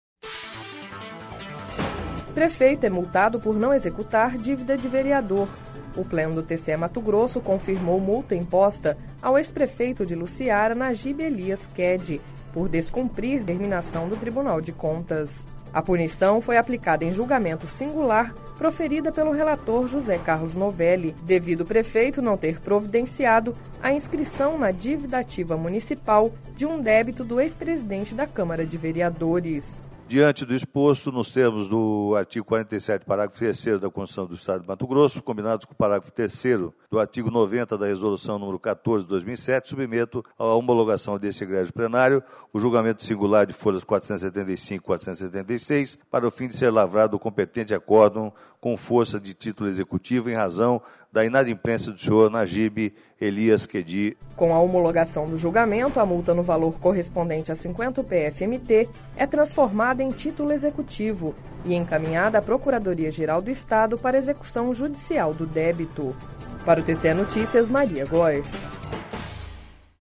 Sonora: José Carlos Novelli – conselheiro do TCE-MT